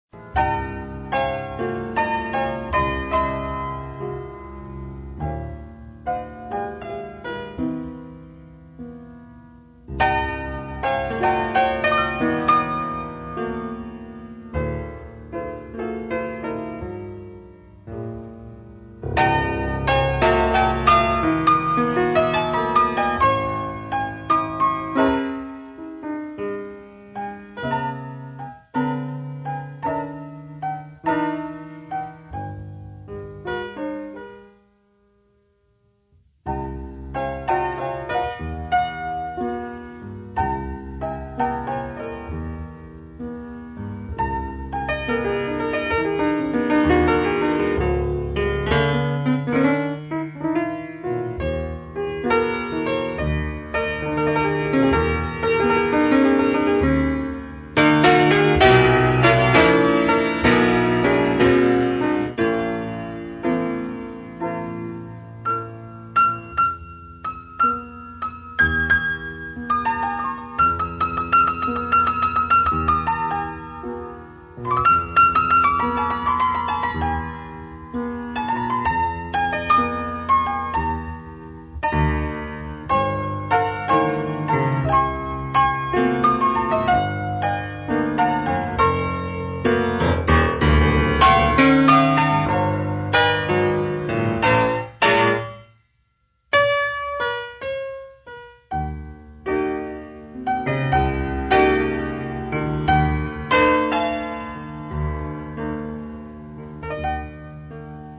a suite of open-ended fantasies at the piano
singing in a jungle of wire strings and felt hammers
where dense dark sonorities find roots